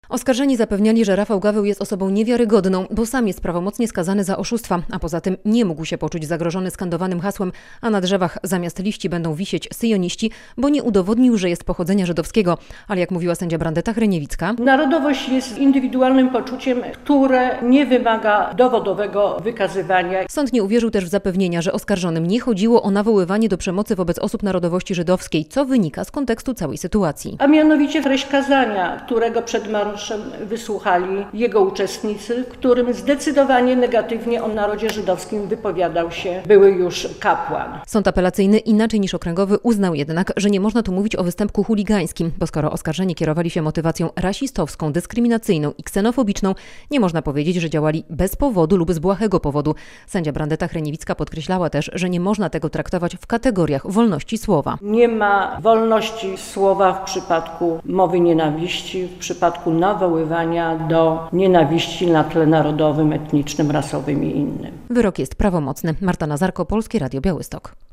Białostocki sąd prawomocnie skazał dwóch mężczyzn oskarżonych o nawoływania do nienawiści podczas marszu ONR - relacja